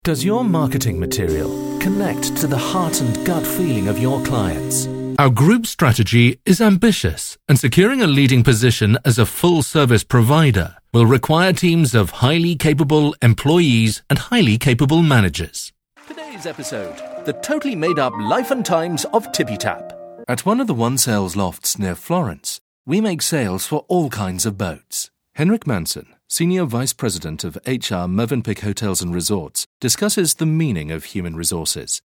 articulate, classic, contemporary, British English, standard BBC
Sprechprobe: Industrie (Muttersprache):
Warm, intelligent and compelling British voice for all audio applications